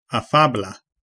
Ääntäminen
UK : IPA : /ˈæf.ə.bəl/